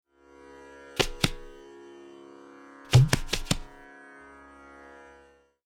(Bols below: recorded on my tabla)
• Dhere (‘Dhir-Dhir’): A loud, ‘full-surface slap’ of the dayan, executed by placing the whole hand further forward than usual, and striking with both sides of the palm in alternation (often as loops of ‘Dhe-Re-Te-Re‘, with the bayan only included every 4 dayan strikes).
TALAS-Bol-Syllable-Dhere.mp3